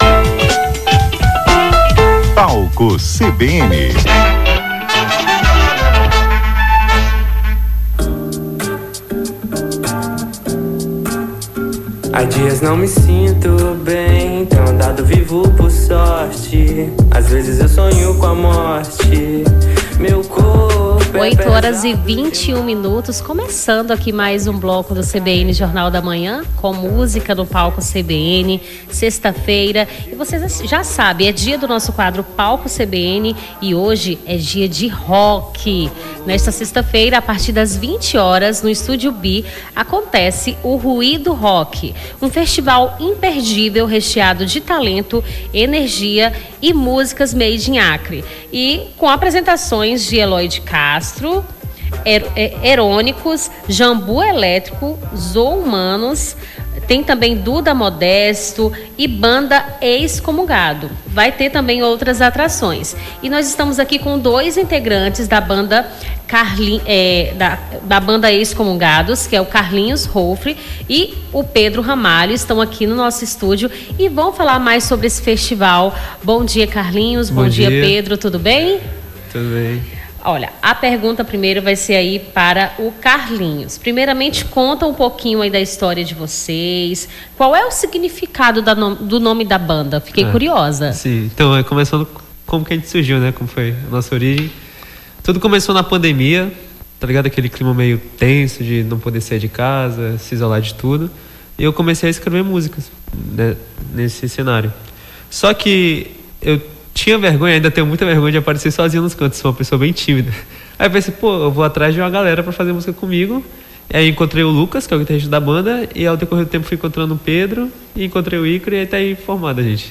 conversou com os integrantes da banda Excomungados